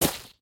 sounds / material / human / step / gravel1.ogg
gravel1.ogg